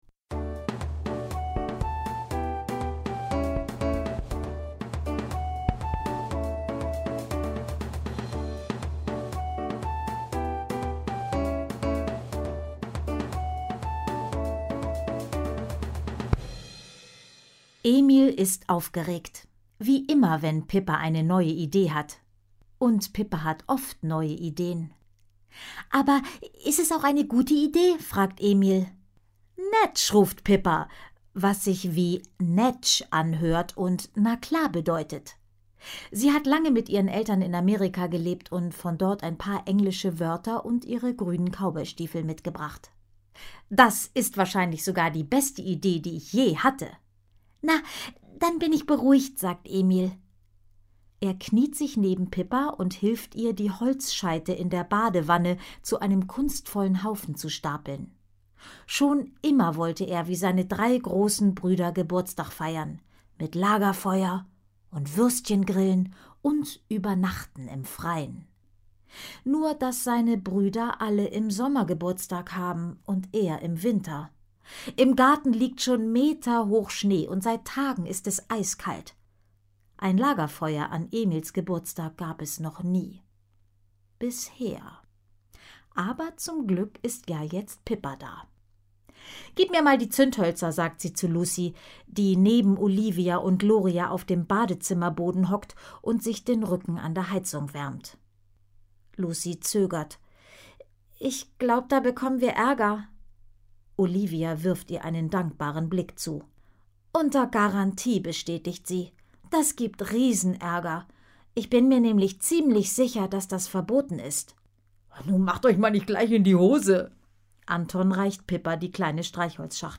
Hörbuch; Lesung für Kinder/Jugendliche